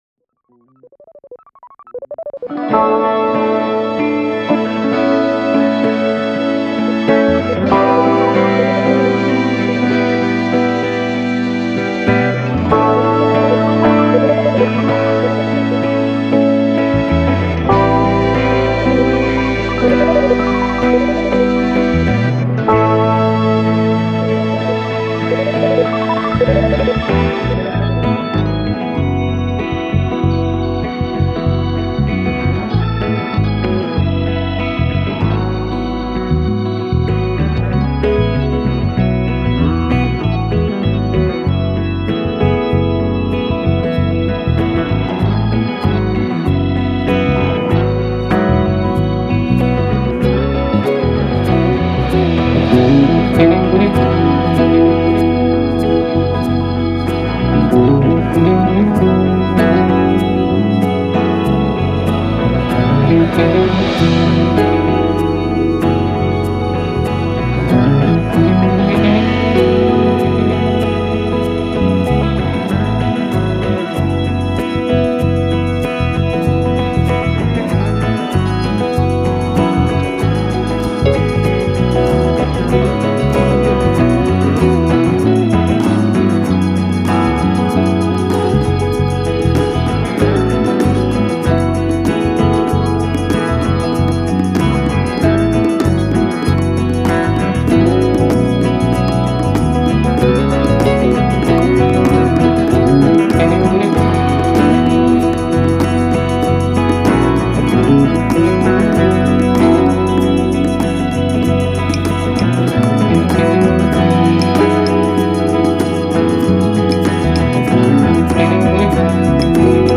Genres:R&B and Soul